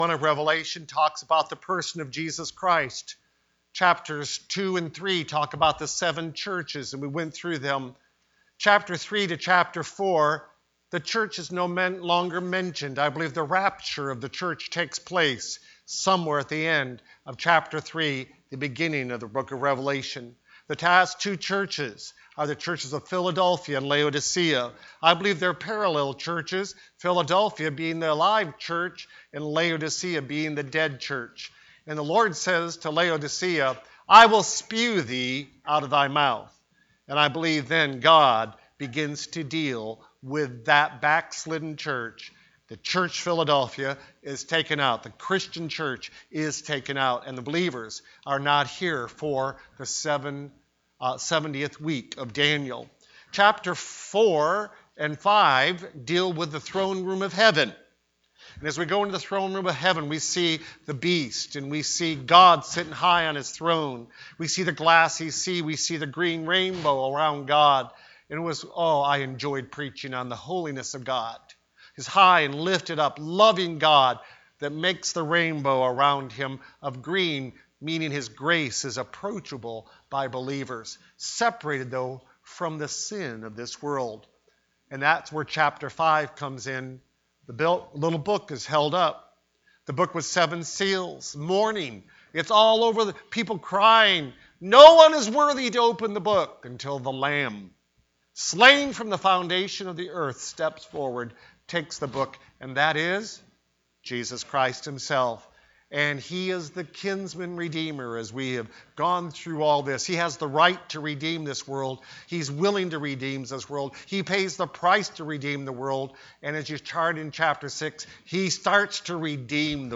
The Revelation Service Type: Sunday Evening Preacher